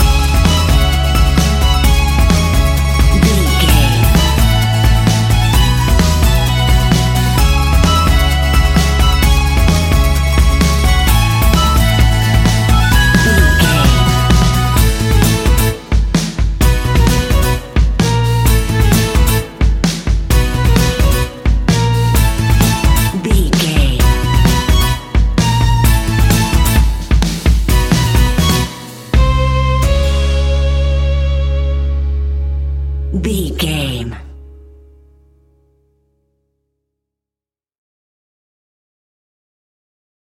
Aeolian/Minor
C#
acoustic guitar
mandolin
ukulele
lapsteel
drums
double bass
accordion